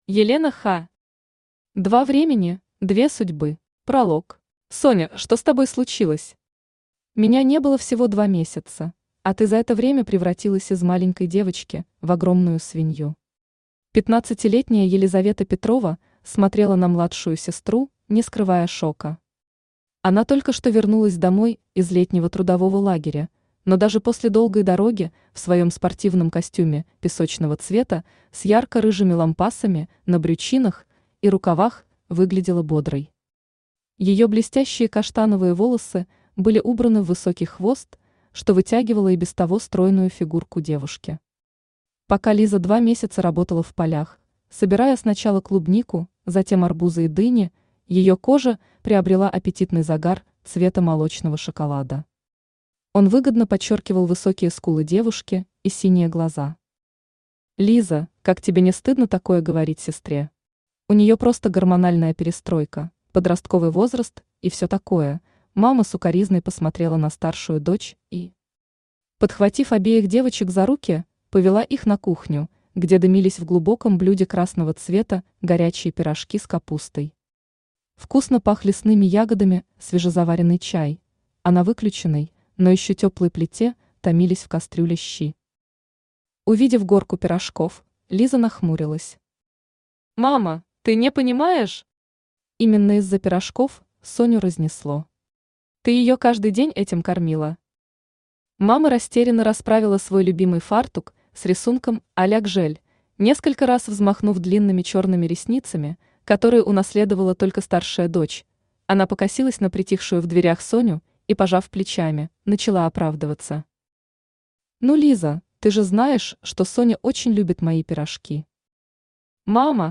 Aудиокнига Два времени – две судьбы Автор Елена Ха Читает аудиокнигу Авточтец ЛитРес.